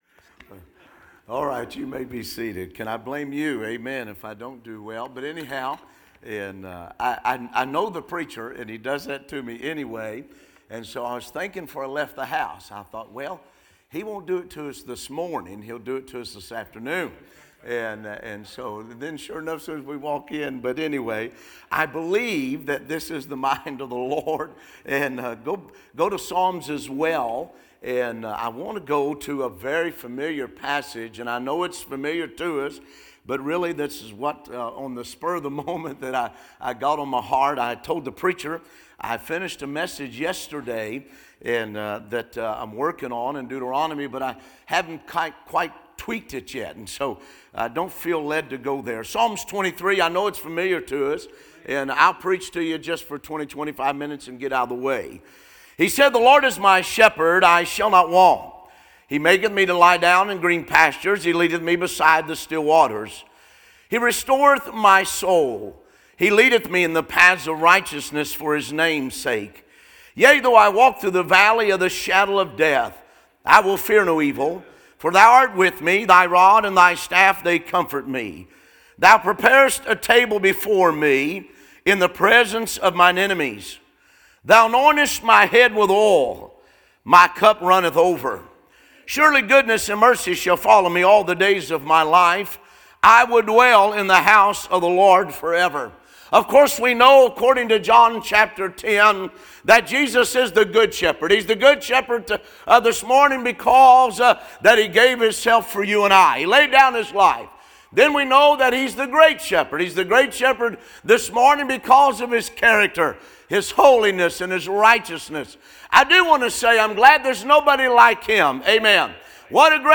A sermon preached Sunday Morning